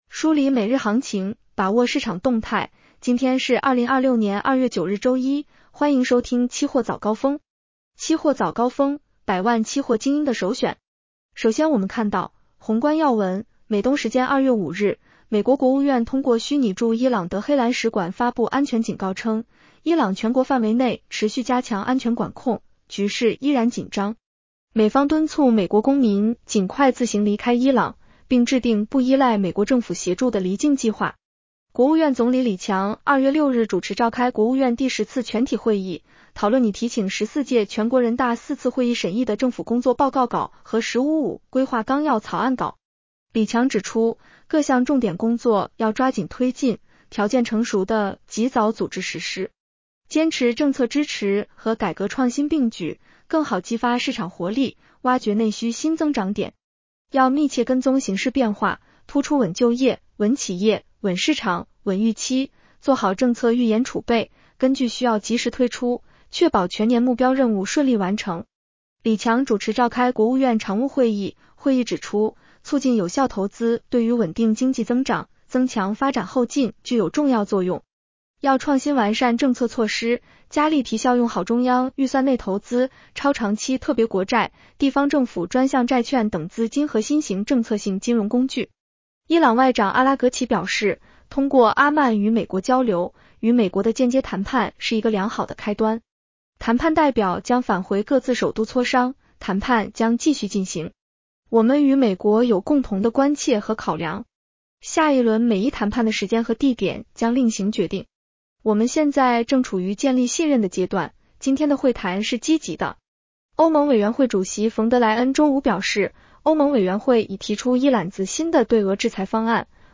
期货早高峰-音频版 女声普通话版 下载mp3 热点导读 1.国常会：要加力提效用好中央预算内投资、超长期特别国债、地方政府专项债券等资金和新型政策性金融工具。